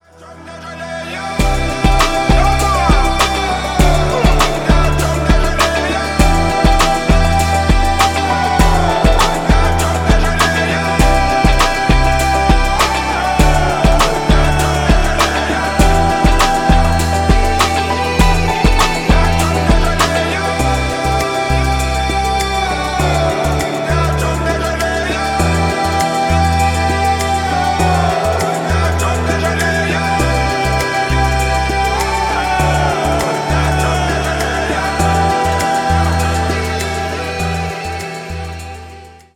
• Качество: 320, Stereo
позитивные
Хип-хоп
романтичные
relax
приятный мужской голос